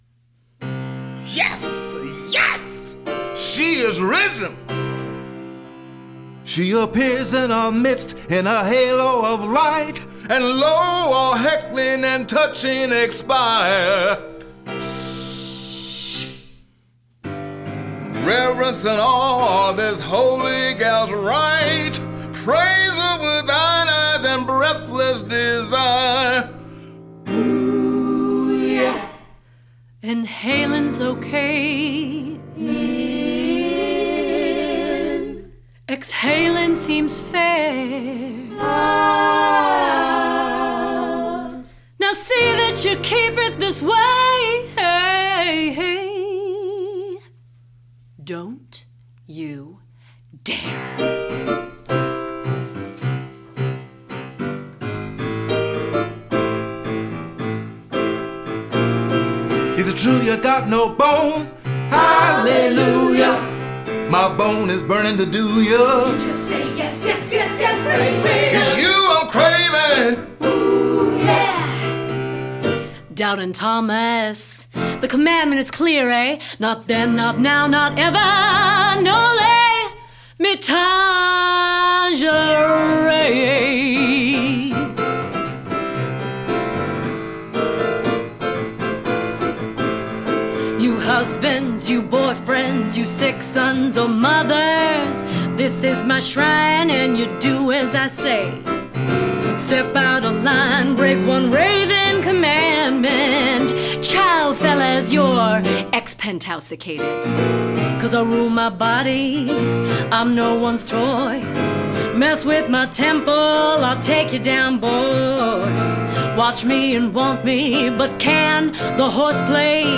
A gospel-inspired show number sung by character Raven and ensemble.
Musical Direction/Piano on all archival tracks